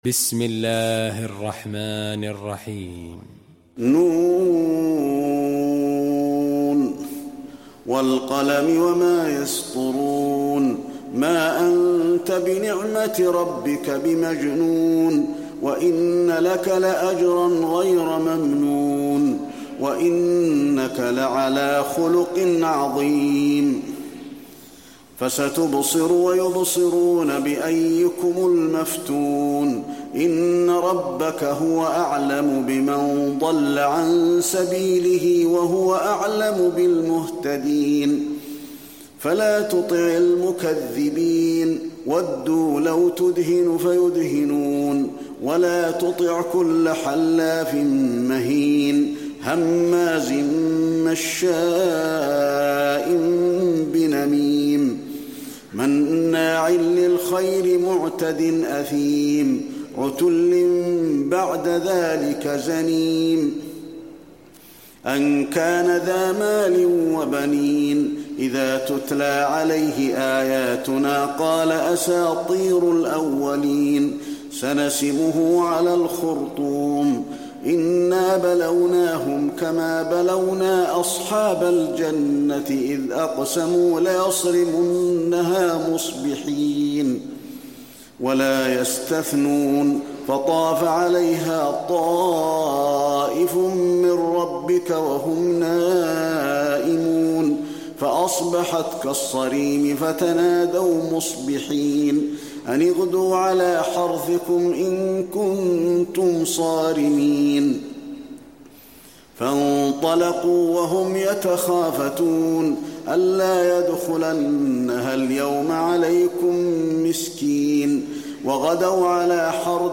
المكان: المسجد النبوي القلم